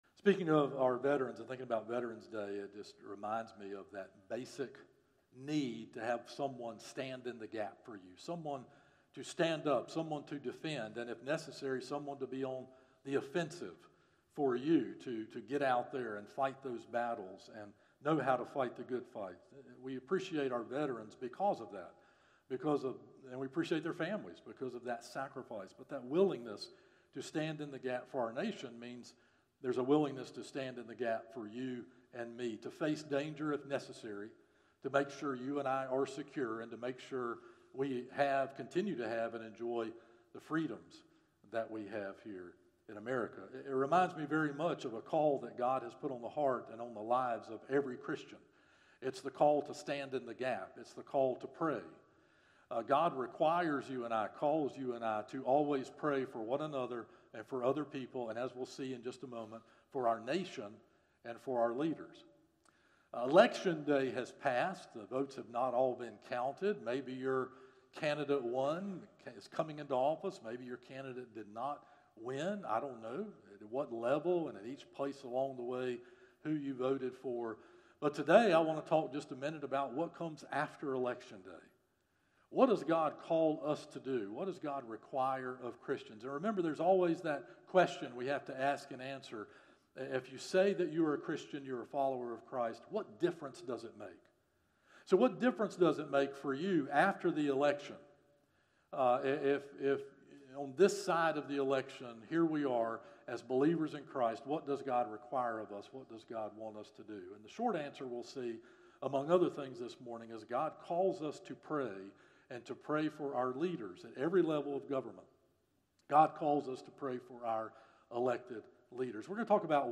Sermons - First Baptist Church of Shallotte
From Series: "Morning Worship - 11am"